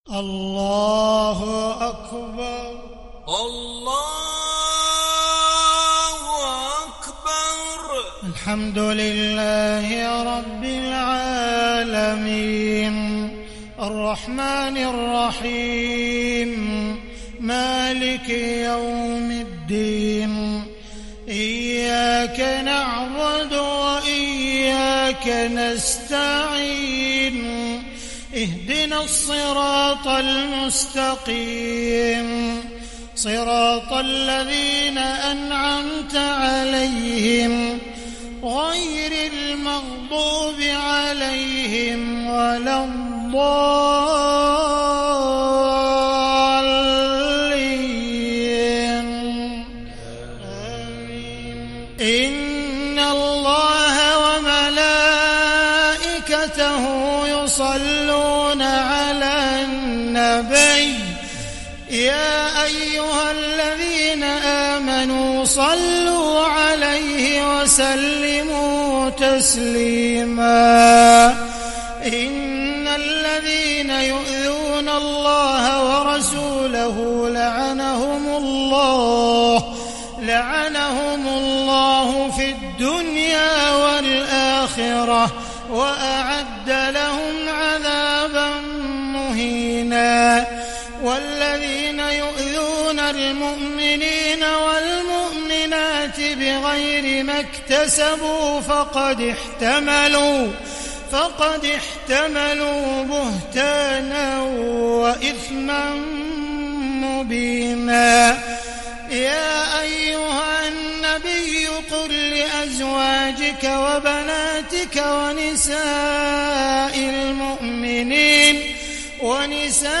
صلاة العشاء للشيخ عبدالرحمن السديس 12 جمادي الآخر 1441 هـ
تِلَاوَات الْحَرَمَيْن .